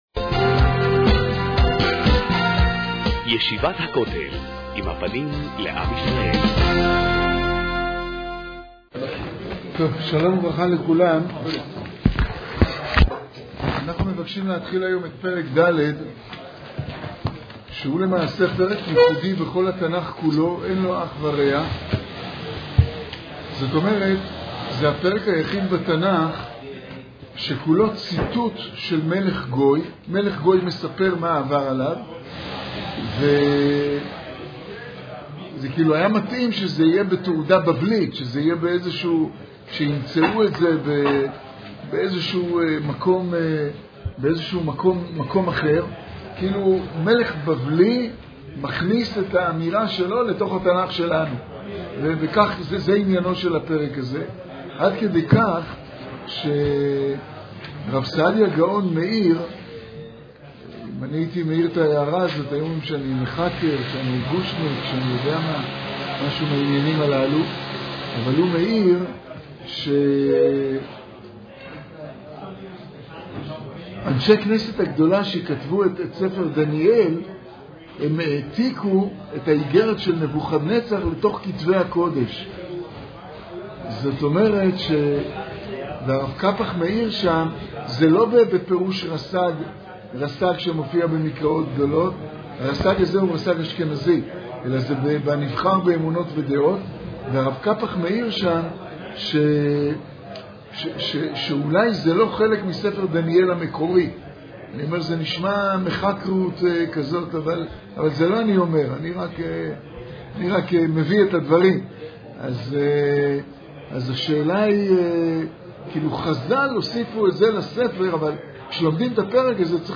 כסלו תש"פ להאזנה לשיעור: https